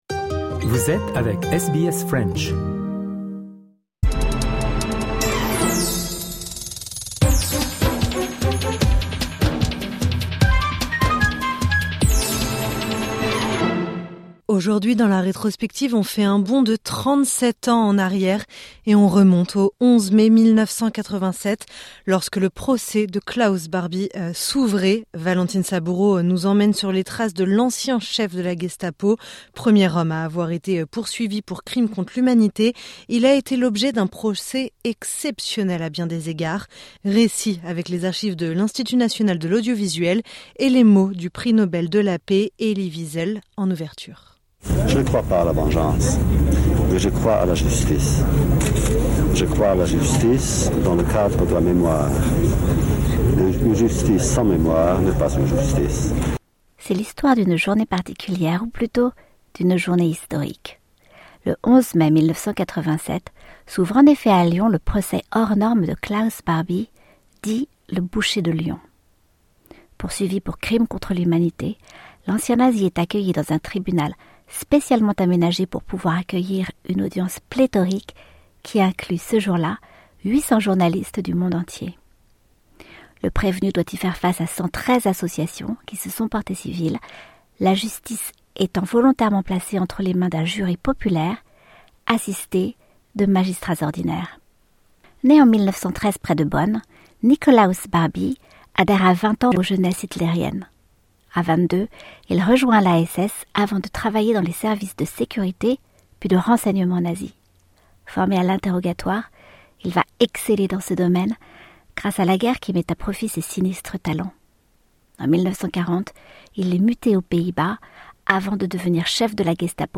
Premier homme à avoir été poursuivi pour crimes contre l'humanité, il a été l'objet d'un procès exceptionnel à bien des égards. Récit, avec les archives de l'Institut national de l'audiovisuel et les mots du prix Nobel de la paix Elie Wiesel en ouverture.